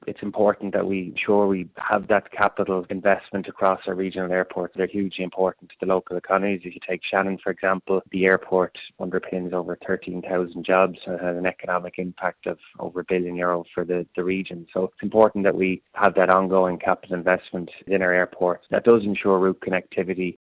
Junior Minister Jack Chambers – who has special responsibility for aviation – has defended the government’s allocation, in terms of aviation’s impact on the environment.